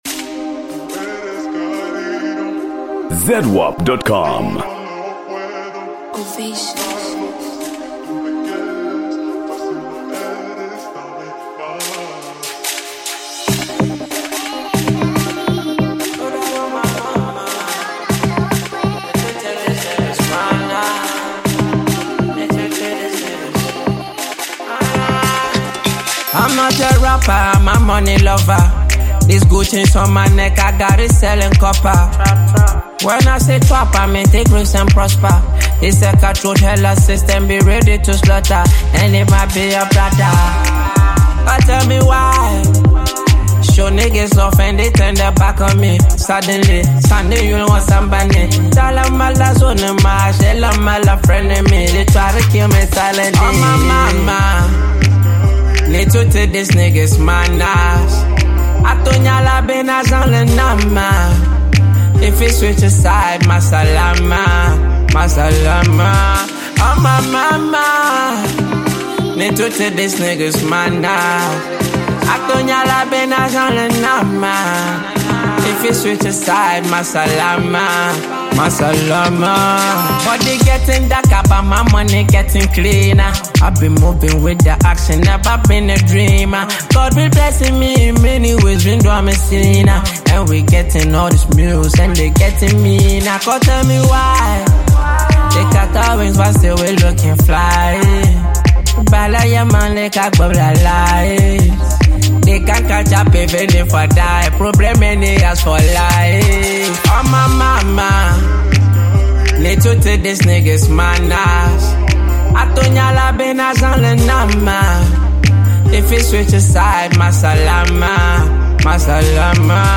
Género musical: Afro Beat